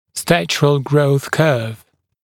[‘stæʧərl grəuθ kɜːv][‘стэчэрл гроус кё:в]кривая роста (пациента)